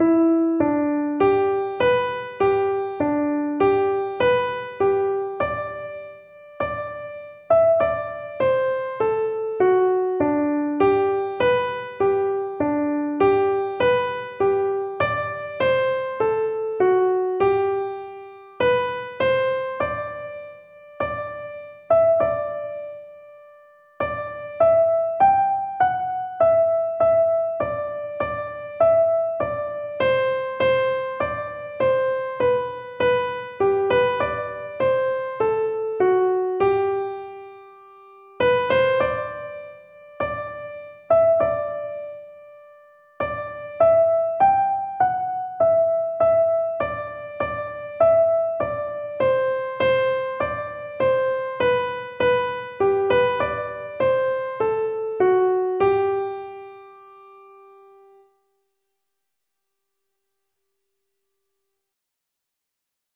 Each tune is available as a PDF (sheet music) and MP3 (audio recording played slowly for learning).